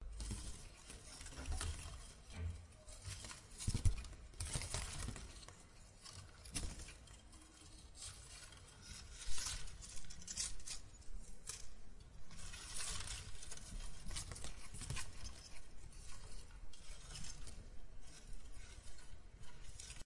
棕榈树的叶子在轻轻地沙沙作响
描述：棕榈叶的柔和沙沙声，或者在家里用ZOOM H1制作